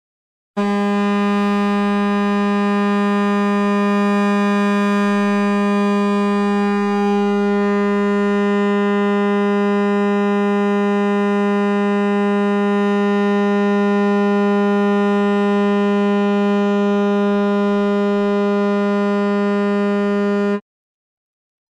Water siren sound
A taster of the water siren sound.
This is being heard across Switzerland on February 3.